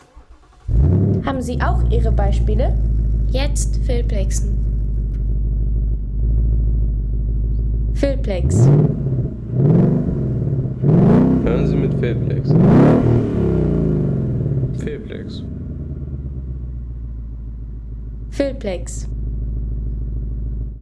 Der satte Sound des Ford Mustang GT 5.0 V8 als Cabrio – mit offenem Himmel, Automatikgetriebe und tiefem Klangspektrum amerikanischer Fahrleidenschaft.